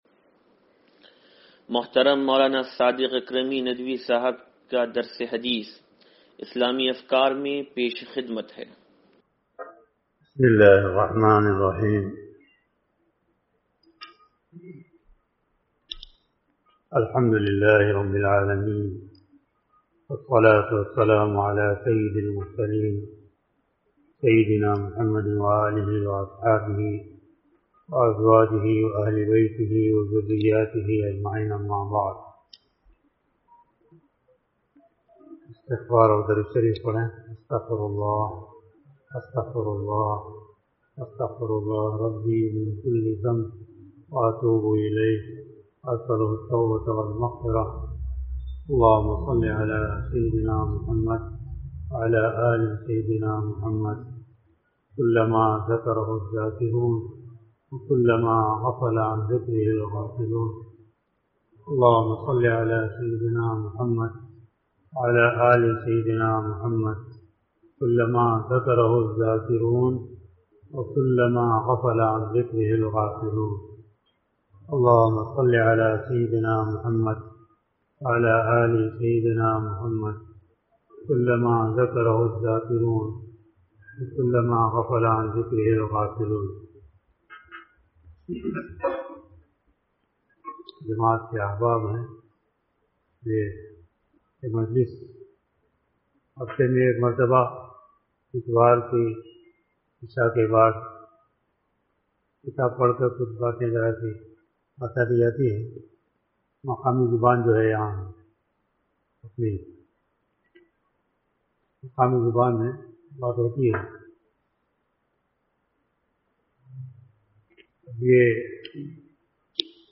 درس حدیث نمبر 0605